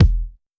DrKick17.wav